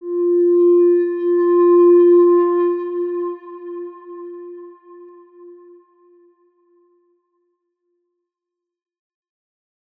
X_Windwistle-F3-ff.wav